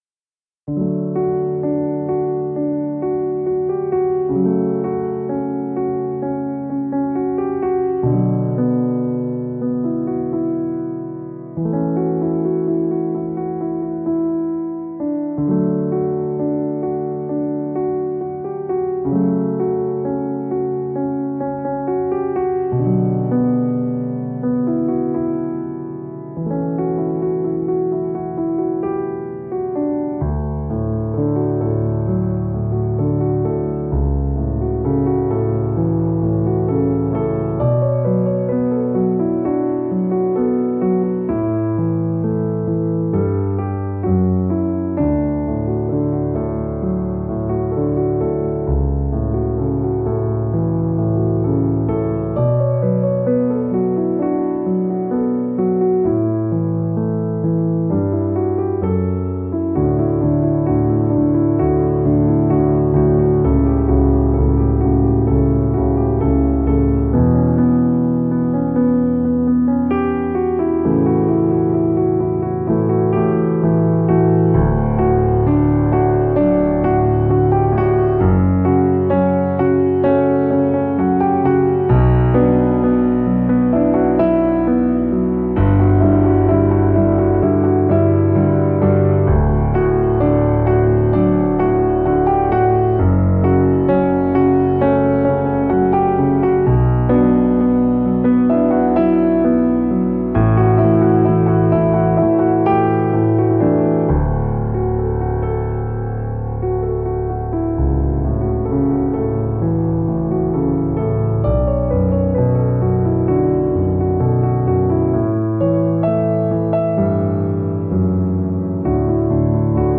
Piano Tutorial/Cover
Piano Tutorial